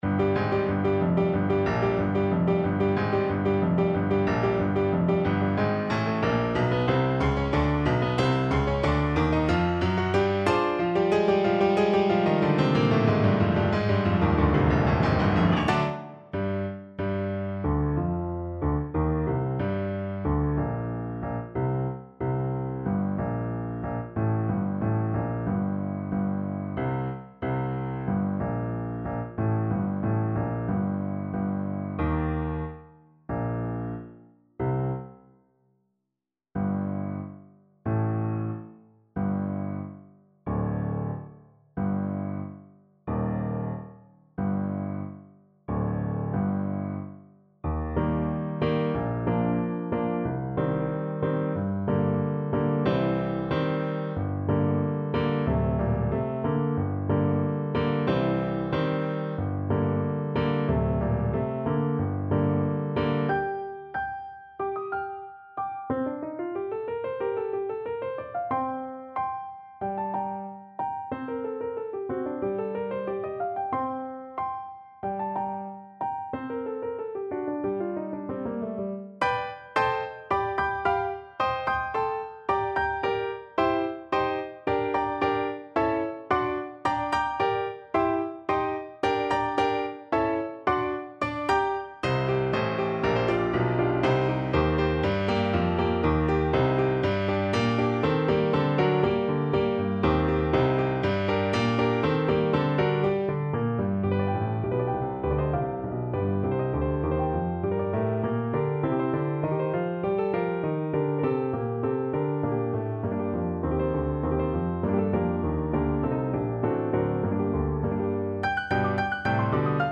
Allegro =92 (View more music marked Allegro)
2/4 (View more 2/4 Music)
Classical (View more Classical Trombone Music)